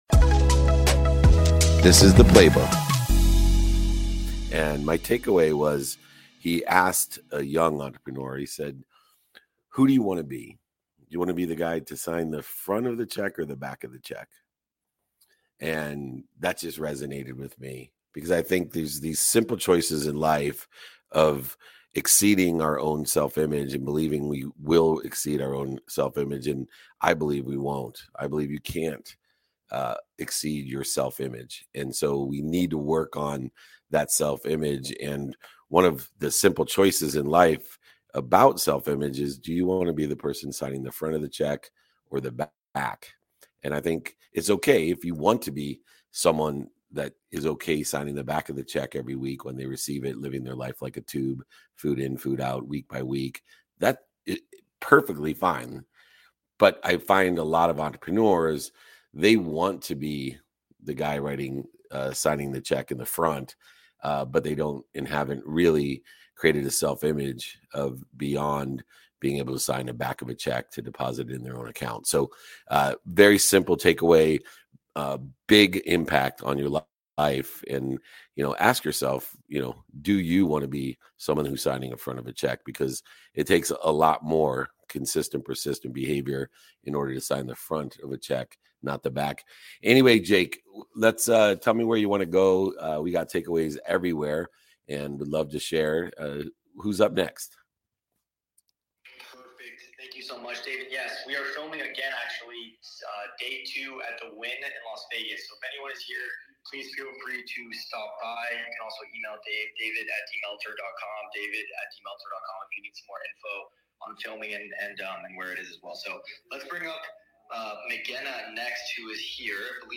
For over 20 years, I've hosted a weekly Friday training in my office sharing tactical and pragmatic advice to empower others to pursue their potential.
Each weekly training provides a new topic with a correlated training guide to provide you with pragmatic strategies to implement in your daily activity to achieve your goals. They take place live every Friday at 7 am PST/10 am EST via a webinar with LIVE Q&A submitted by attending guests.